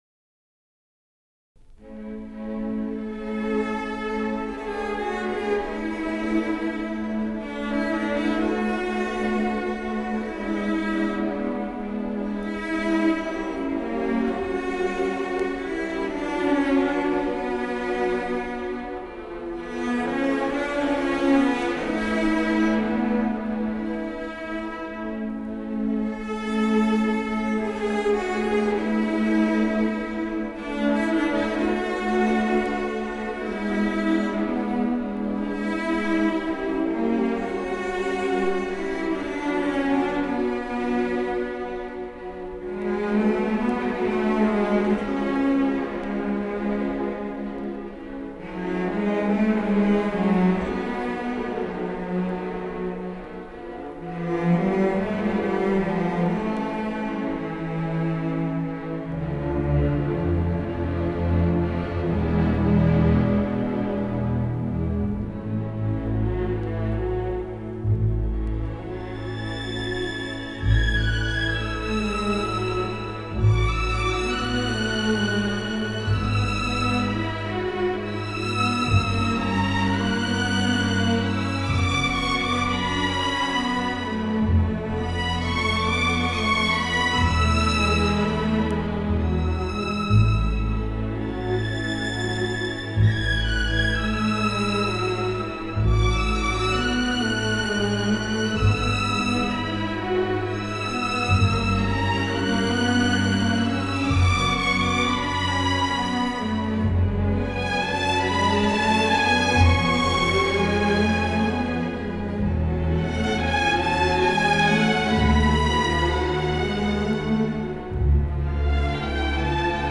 borodin_nocturno-camarata-y-orquesta-sinfónica-kingsway.mp3